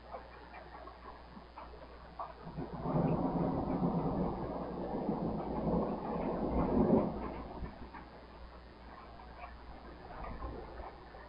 自然的声音 " 雷霆室内4
描述：记录从室内，雷鸣般的雷鸣。雷电雷雨天气雷雨滚滚雷声隆隆声
Tag: 闪电 天气 滚动雷 雷暴 雷暴 隆隆